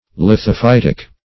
lithophytic - definition of lithophytic - synonyms, pronunciation, spelling from Free Dictionary
Search Result for " lithophytic" : Wordnet 3.0 ADJECTIVE (1) 1. of or relating to lithophytes ; The Collaborative International Dictionary of English v.0.48: Lithophytic \Lith`o*phyt"ic\, a. (Zool.)